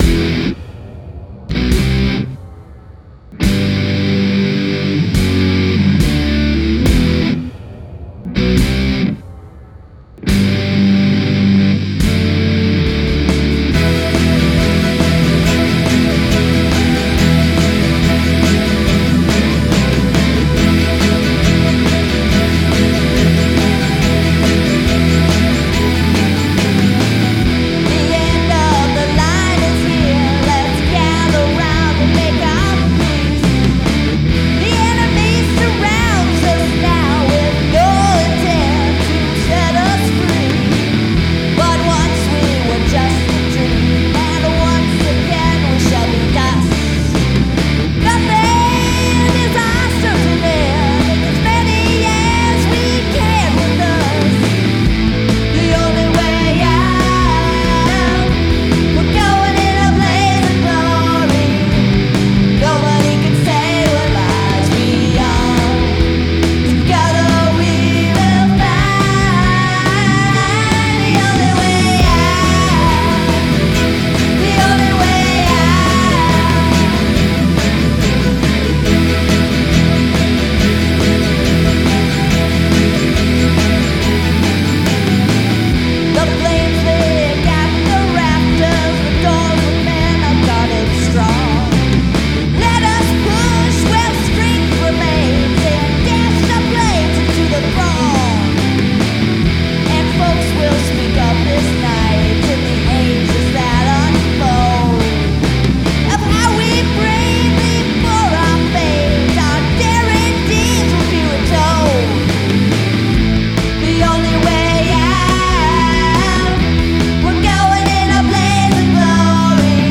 Downward Modulation